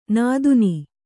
♪ nāduni